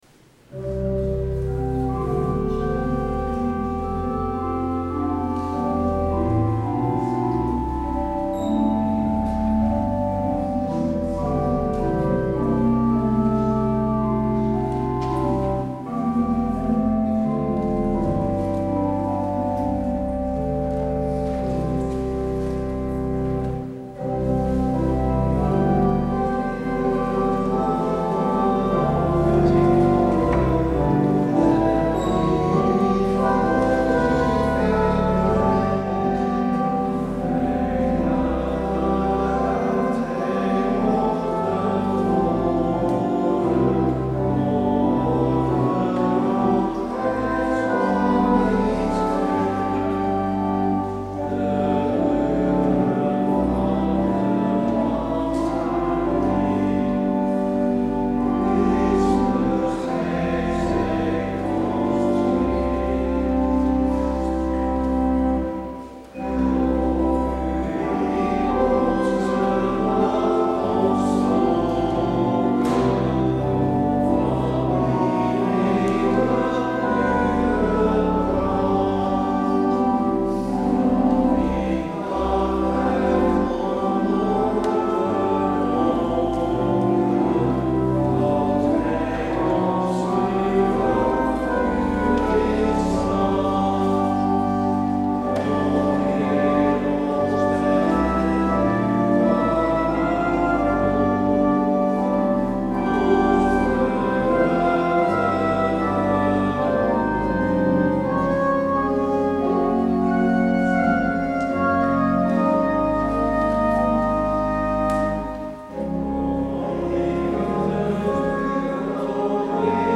 Luister deze kerkdienst hier terug
Als openingslied hoort u: Lied 103e – Bless the Lord, my soul.